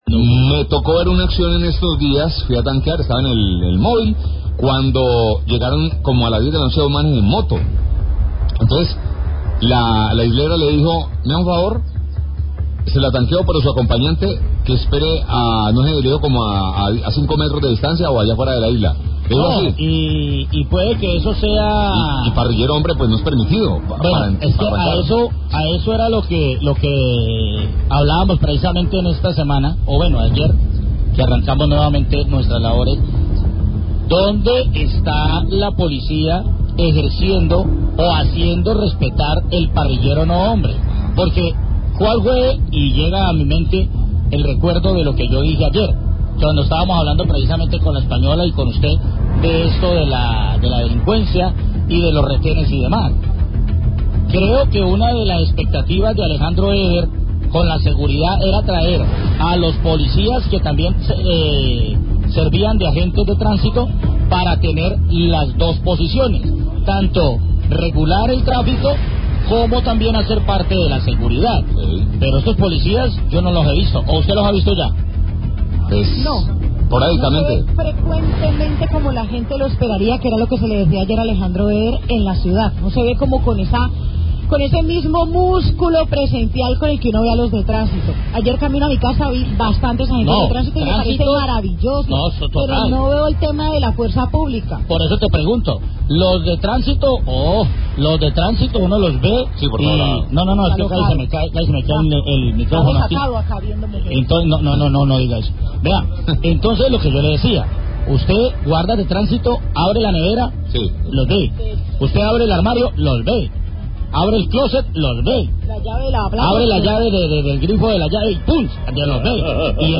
Periodistas preguntan por policías de tránsito y por qué no ayudan combatiendo inseguridad
Radio